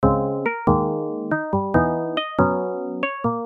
描述：Hardbass Hardstyle Loops 140 BPM
Tag: Hardbass 硬派音乐